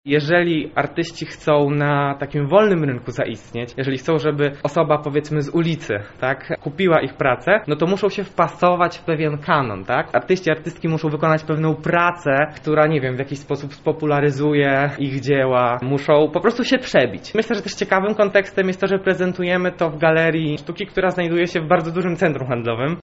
podczas oprowadzania po ekspozycji.